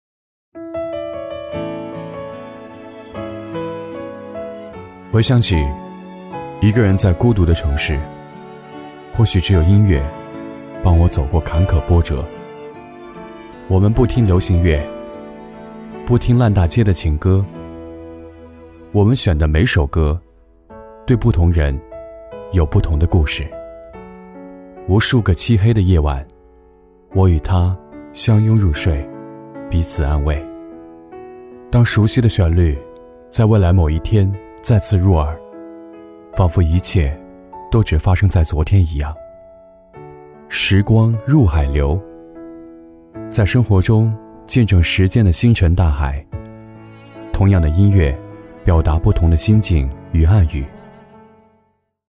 旁白-男35-孤独的城市.mp3